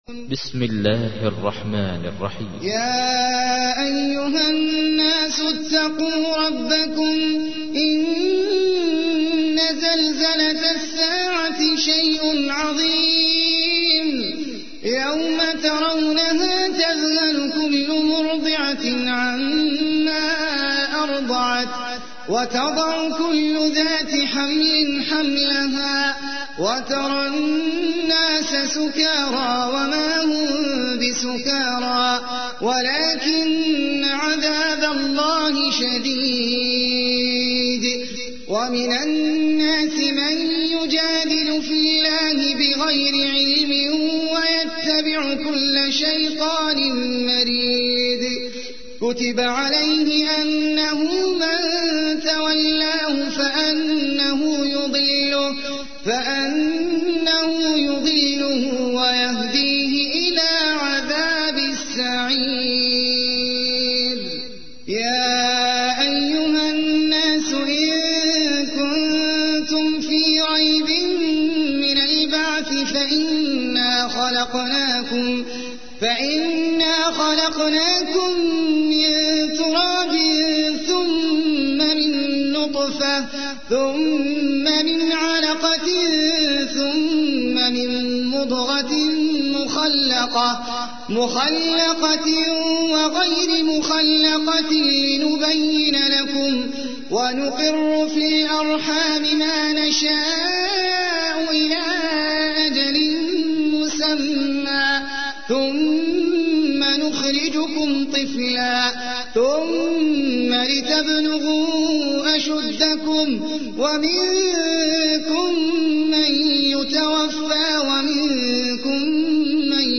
تحميل : 22. سورة الحج / القارئ احمد العجمي / القرآن الكريم / موقع يا حسين